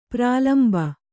♪ prālamba